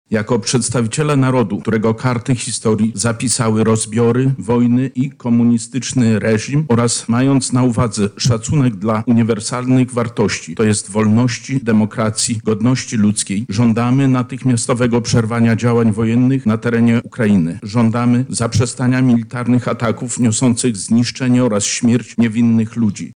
• mówi Wicemarszałek Województwa Lubelskiego Zbigniew Wojciechowski.